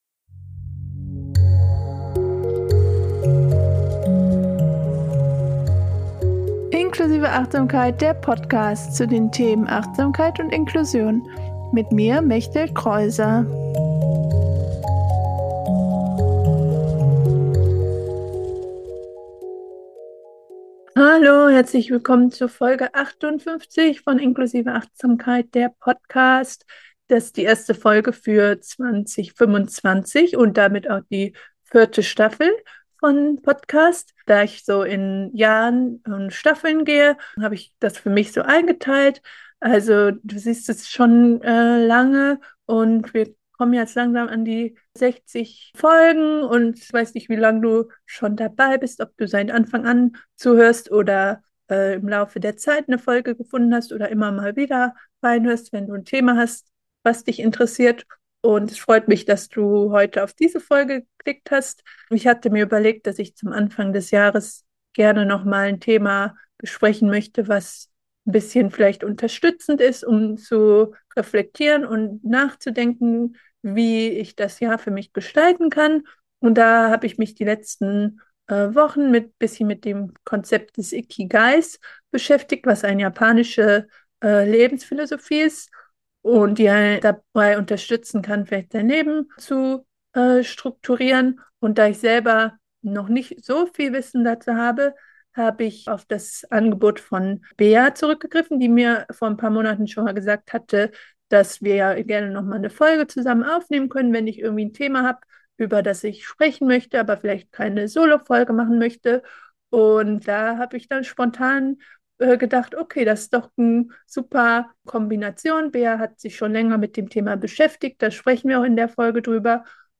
Anstatt nur eine Solo-Folge oder nur ein Interview zu machen, ist dies ein Mix aus einem Thema, dass ich in einer Solo-Folge besprechen würde und einem Interview oder Gespräch.